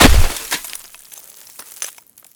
Add sound for clicking the card
frozen1.ogg